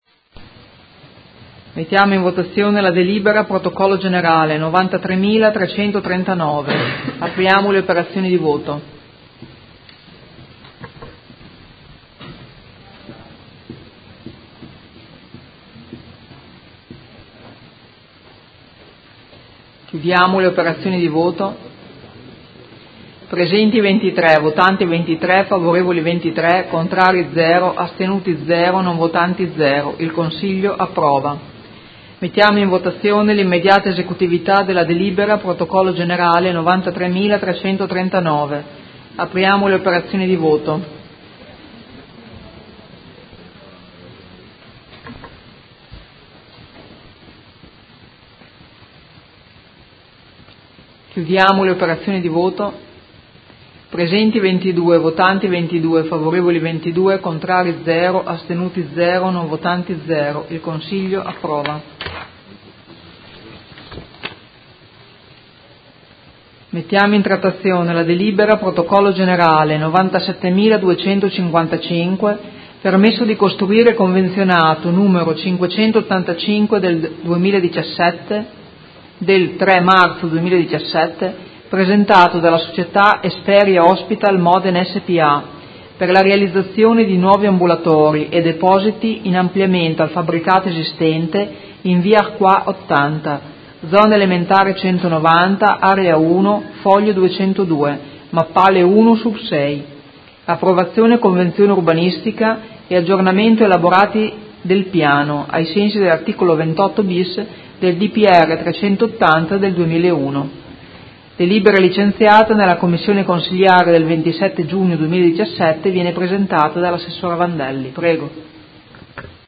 Presidentessa